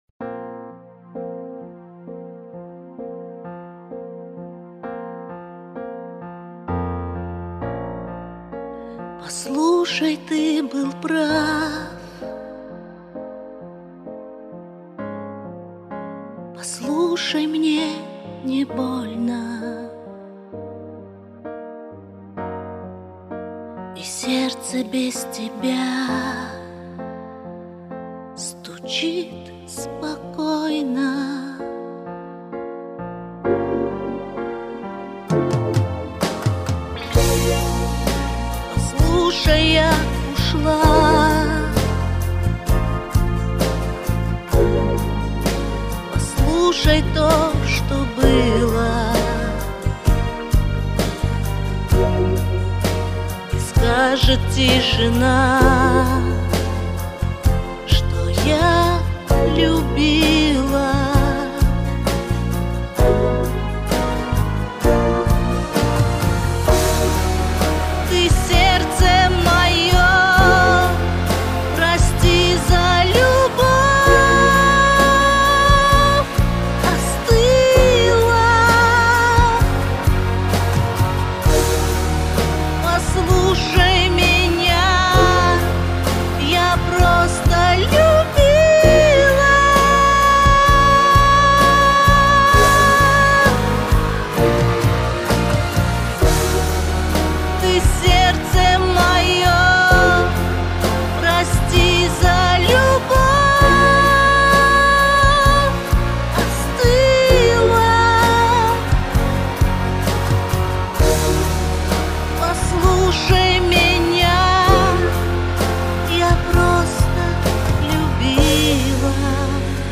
надрыв и боль в голосе